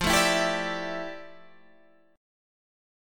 FM13 chord